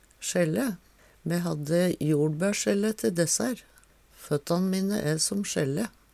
Tilleggsopplysningar anna trykk på ordet på dialekt